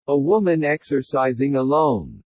（音声は個人的にパソコンを使って作成したもので、本物の話者ではありません。